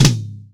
TOM     2B.wav